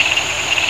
Index of /90_sSampleCDs/Roland LCDP11 Africa VOL-1/SFX_Afro Jungle/SC _Afro Jungle
AM  SWAMP 2.wav